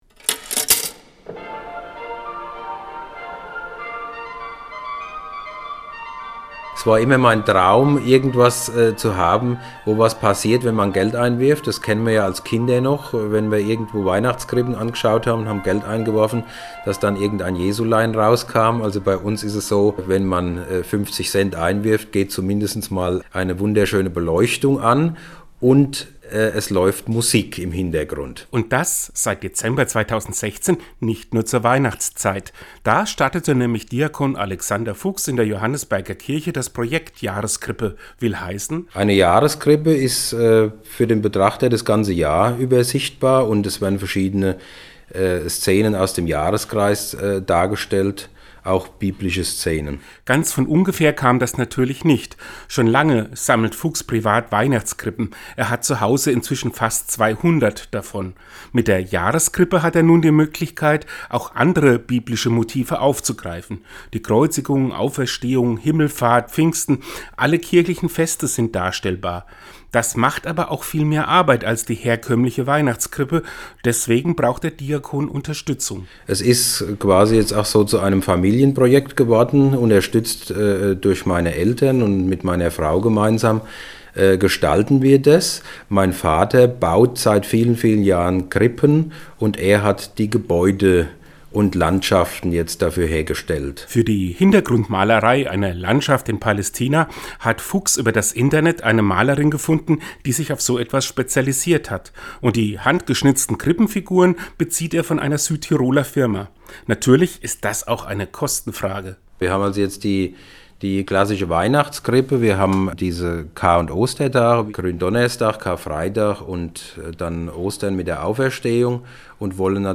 Seinen Radiobeitrag finden Sie unten als Download.